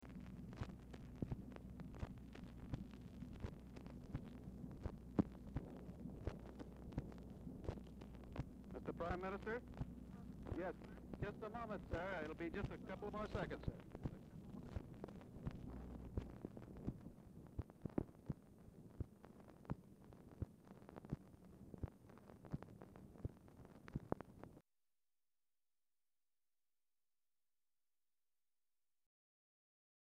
Format Dictation belt
Speaker 2 ALEC DOUGLAS HOME Specific Item Type Telephone conversation Tags Digital Item Time Period Presidential (Nov. 22, 1963-Jan. 20, 1969) Who Placed The Call?